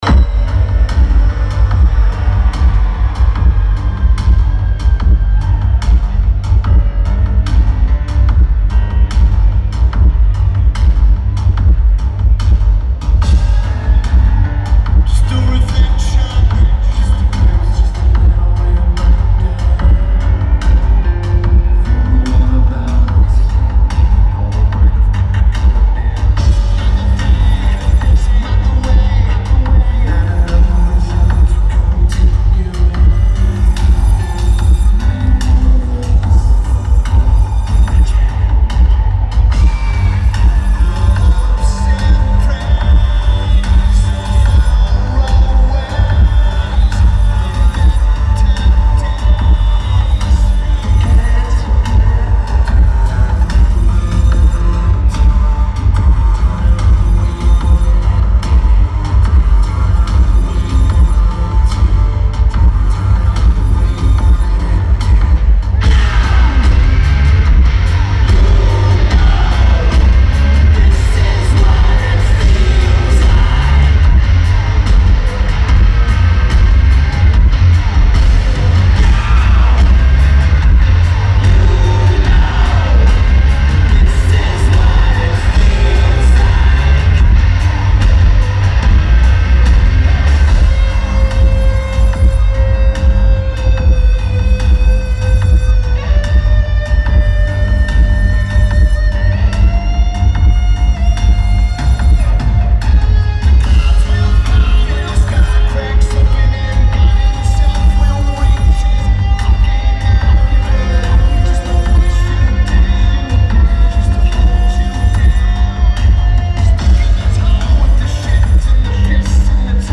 Rabobank Arena
Lineage: Audio - AUD (Olympus WS-852)
The recording is bassy and is mono only.
For a voice recorder, the tape is actually pretty good.